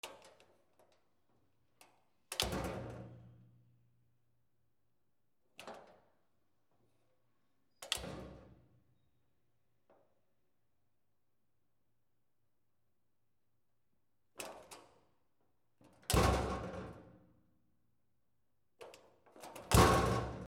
マンション 玄関のドア 強く開け閉め
/ K｜フォーリー(開閉) / K05 ｜ドア(扉)